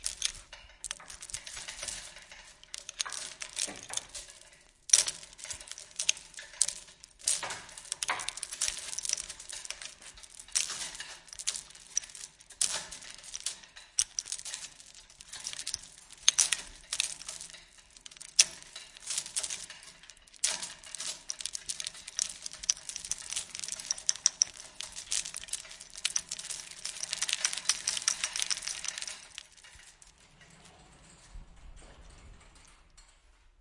金属加工厂 " 金属加工厂的提升机链条粗大的响声和咔哒声在一起
描述：金属车间葫芦链厚厚的拨浪鼓和clack together.flac
Tag: 提升机 金属 拨浪鼓 店铺